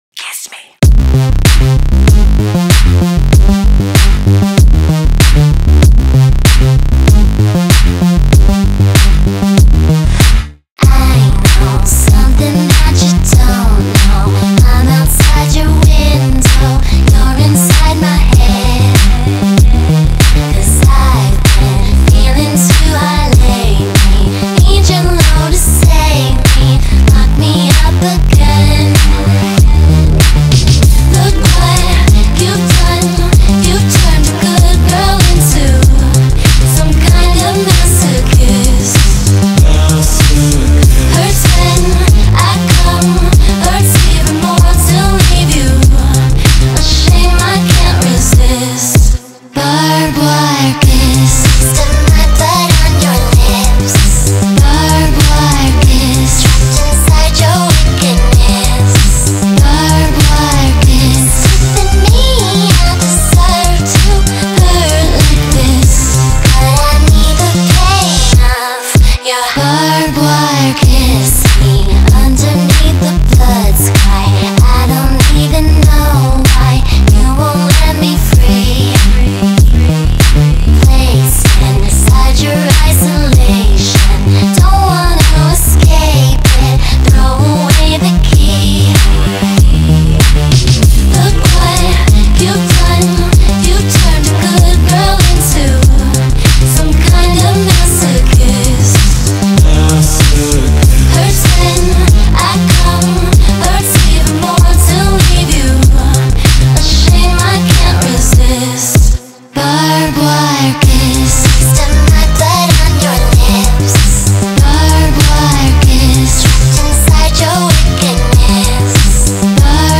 • Жанр: Electronic